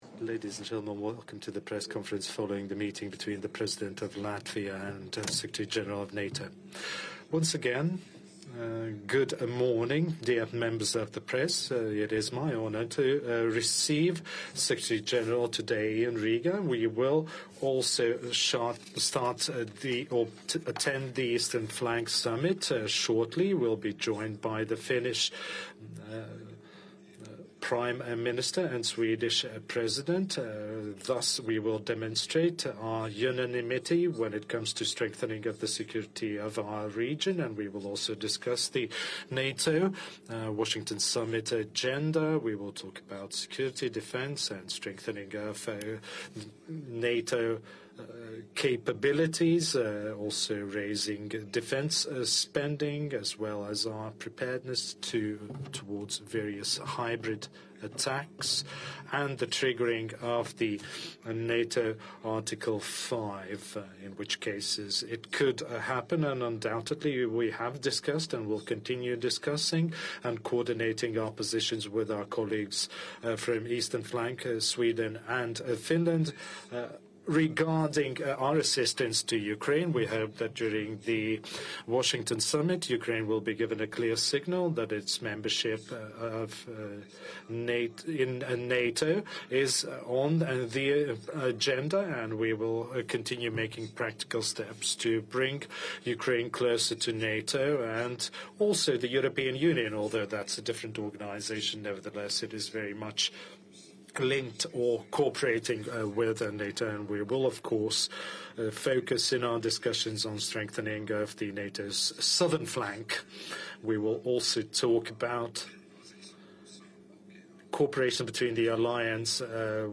ENGLISH - Joint press conference by NATO Secretary General Jens Stoltenberg with the President of Latvia, Edgars Rinkēvičs 11 Jun. 2024 | download mp3 ORIGINAL - NATO Secretary General Jens Stoltenberg is awarded the Order of the 3 Stars by the President of Latvia, Edgars Rinkēvičs 11 Jun. 2024 | download mp3